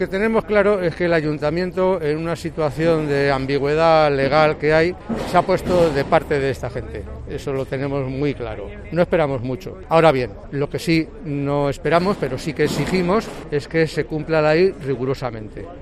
un vecino afectado por las cocinas fantasma